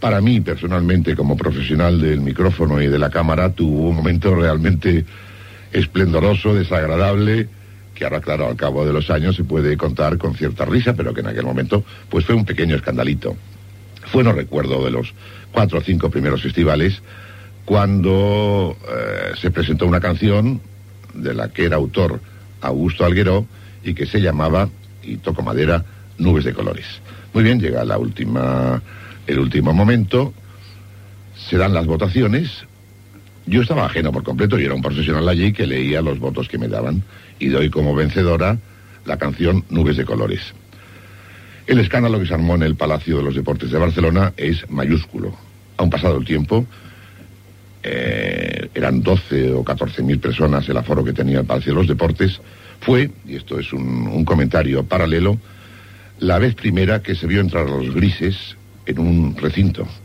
Divulgació
Programa presentat per Joan Manuel Serrat.
Fragment extret del programa "La radio con botas", emès per Radio 5 l'any 1991